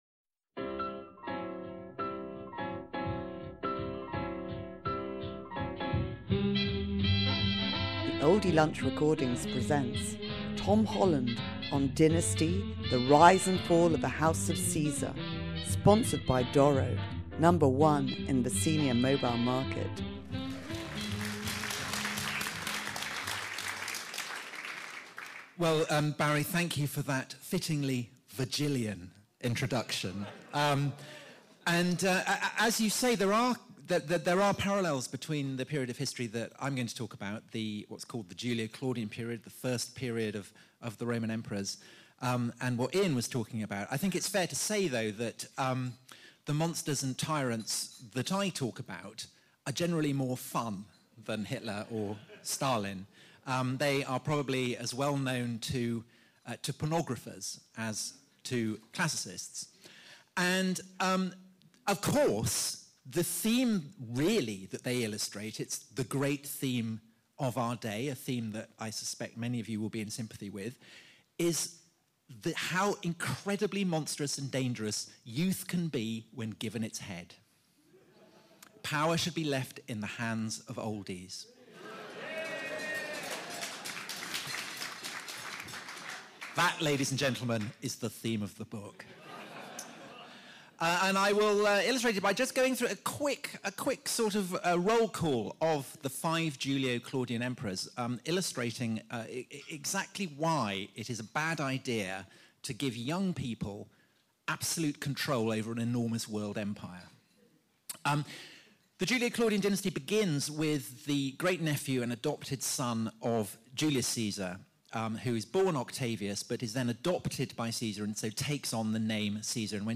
Classicist and historian Tom Holland talks at the renowned Oldie Literary Lunch at Simpsons on the Stand, London W1. Dynasty - The Rise & Fall of the House of Caesar is a prequel to Persian Fire and Rubicon.